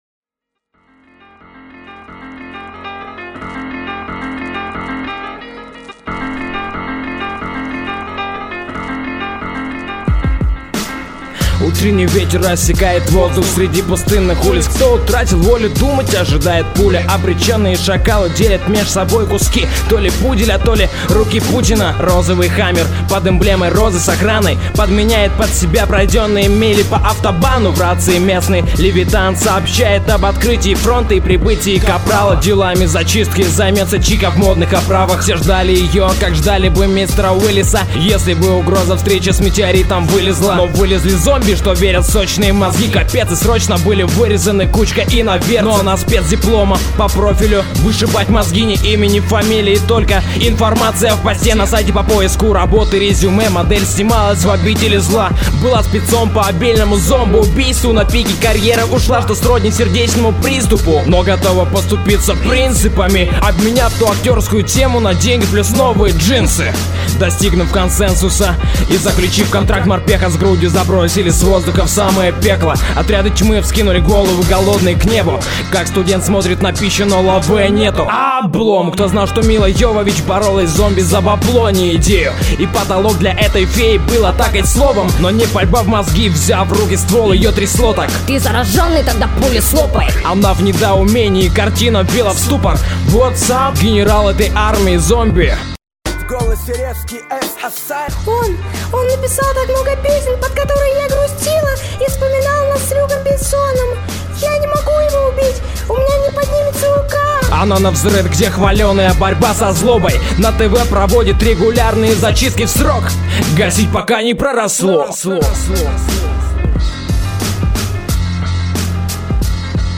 Псевдо Хоррор рэп.